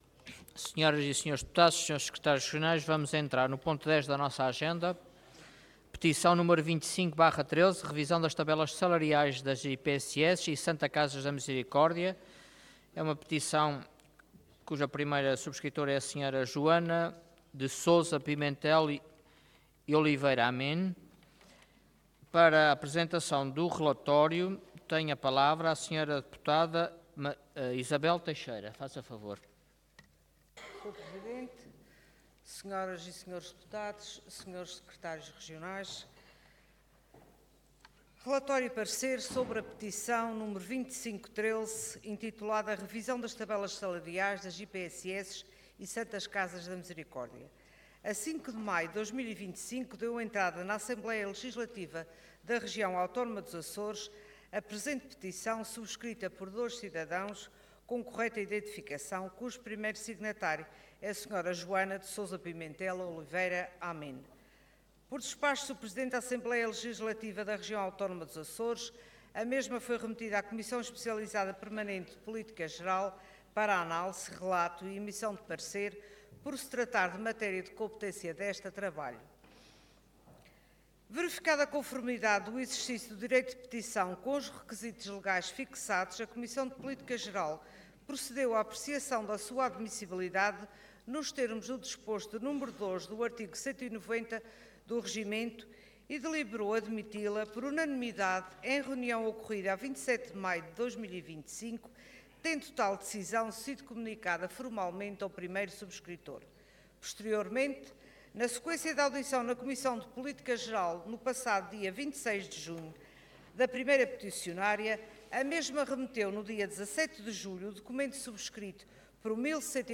Parlamento online - Revisão das tabelas salariais das IPSS e Santas Casas da Misericórdia